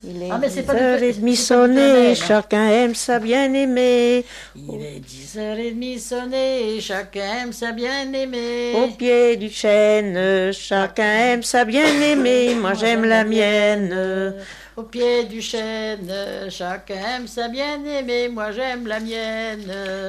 gestuel : à marcher
Genre énumérative
chansons traditionnelles
Pièce musicale inédite